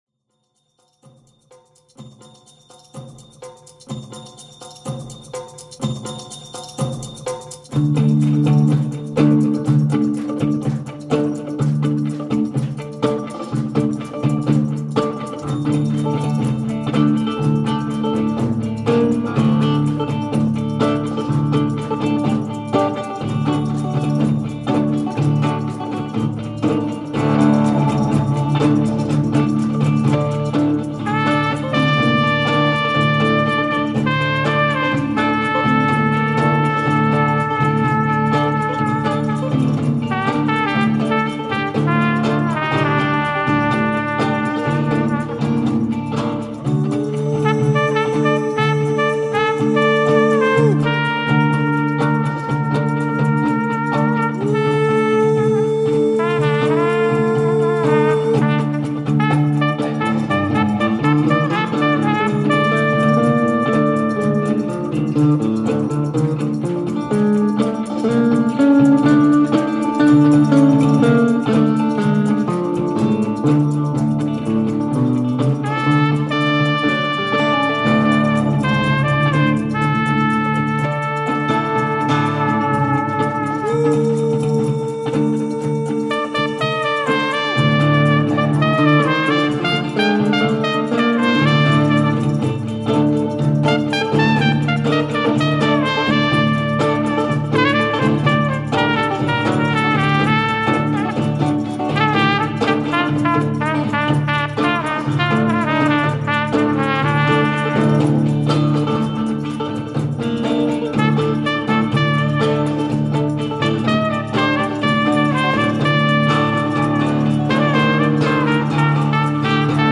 trumpet
guitar/percussion/vocal